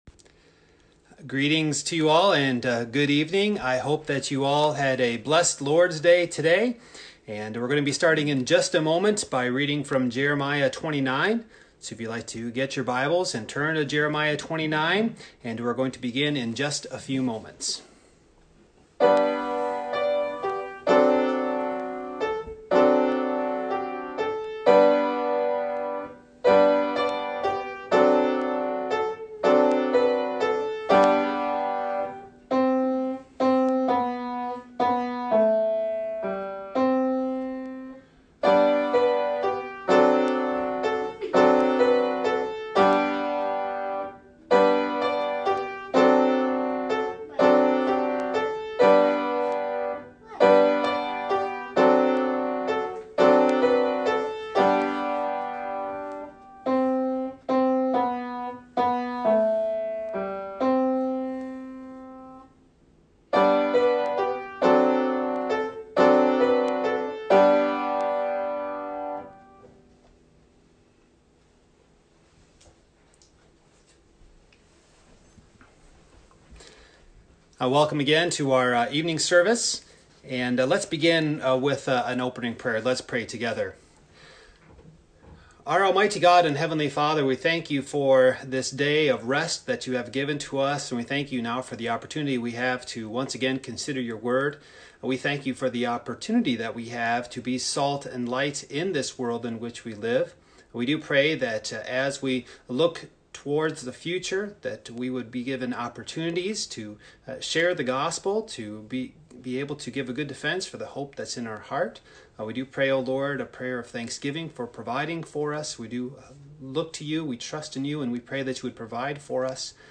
Series: Single Sermons
Service Type: Evening